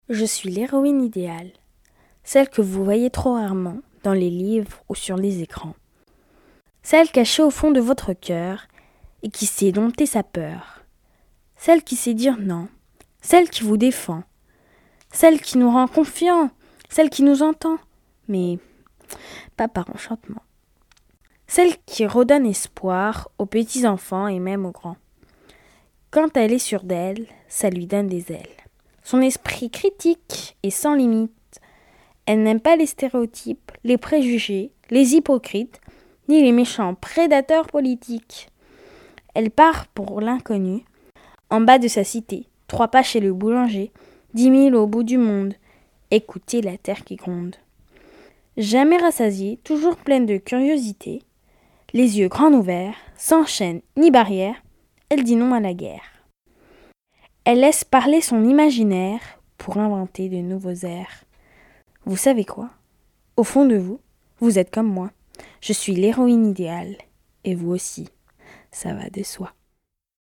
J'ai donc fait 192 portraits sonores, enregistrant chacune des participantes dans la lecture d'un texte de son choix : littérature, essai, poésie...chansons, qui pouvaient être des textes personnels.
Michelle Perrot lit Virginia Woolf